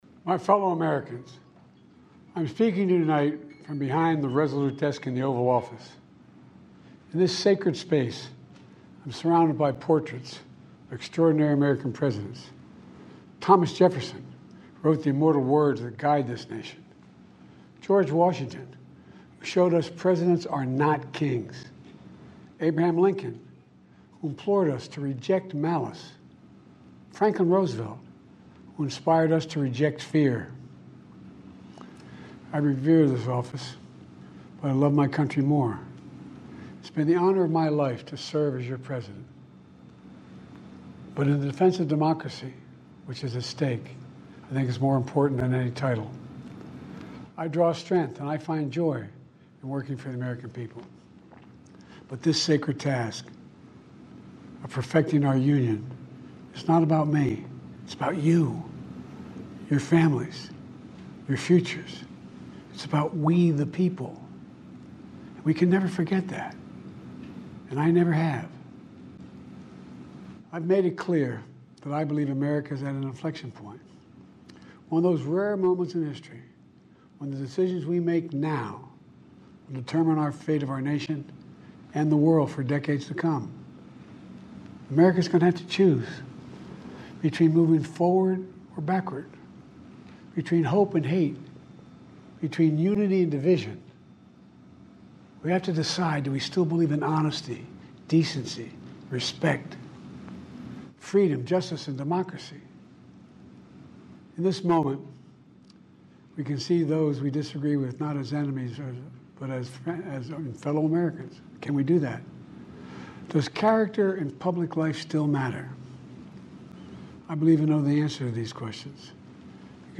Joe Biden Prime Time Address on Not Seeking Presidential Re-Election (transcript-audio-video)